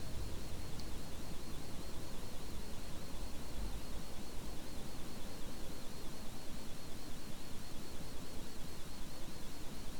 ▶ Sound of MEMS with bit mismatch and 50cm shielded cables, mono
The “synth” is quiter but still noticeably there.
2_mems_mono_16bit_shilded_medium-cable.wav